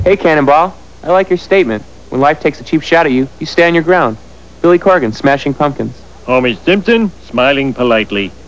Billy et homer se présentent (WAV)